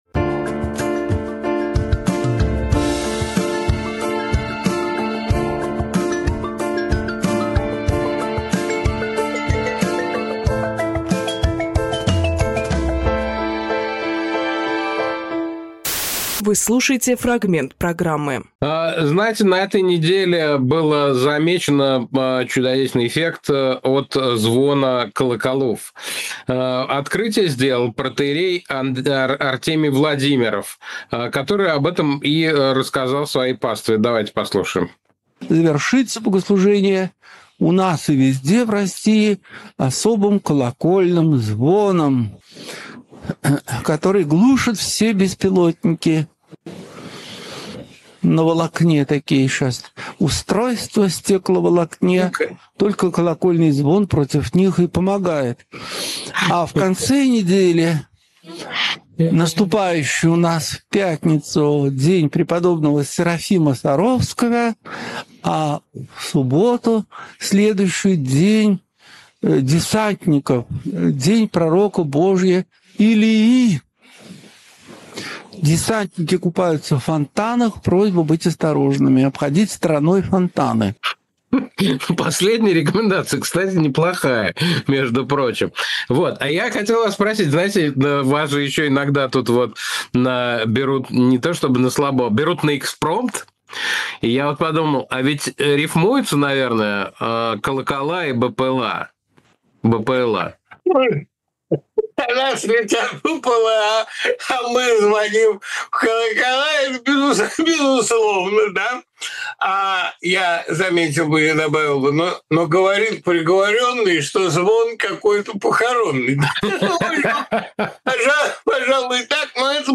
Фрагмент эфира от 03.08.25